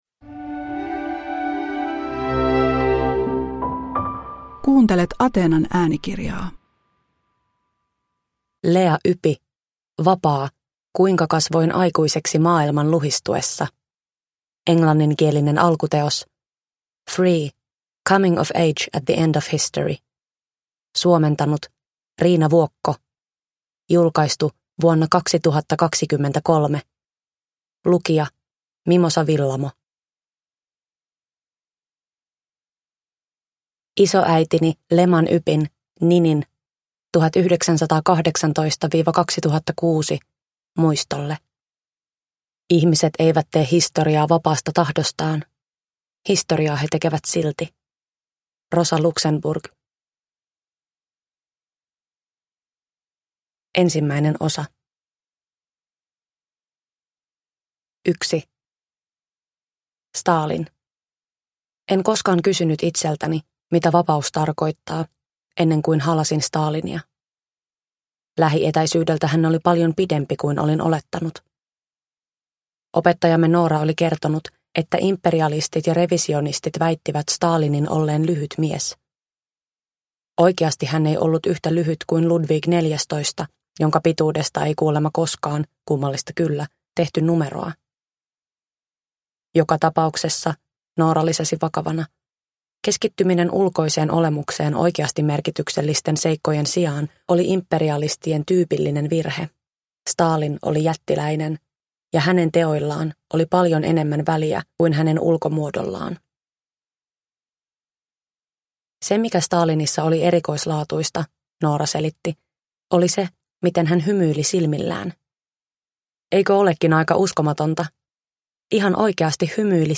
Vapaa – Ljudbok